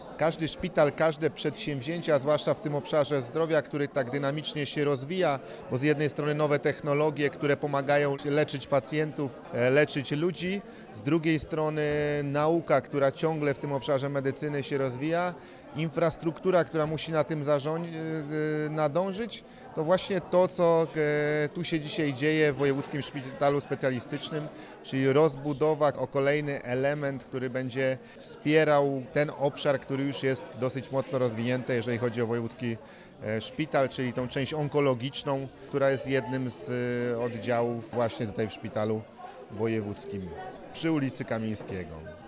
– To bardzo ważna inwestycja w rozwój placówki, która służy mieszkańcom Wrocławia i Dolnego Śląska już od ponad 40 lat. Rozbudowa Wojewódzkiego Szpitala Specjalistycznego zwiększy dostępność oraz jakość diagnostyki i leczenia chorób nowotworowych. Nowa infrastruktura umożliwi szybsze wykonywanie badań oraz usprawni funkcjonowanie poradni onkologicznych, co ma kluczowe znaczenie dla mieszkańców Wrocławia i Dolnego Śląska – mówi Paweł Gancarz, marszałek Województwa Dolnośląskiego.